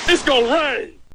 Worms speechbanks
incoming.wav